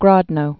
(grôdnō, -nə)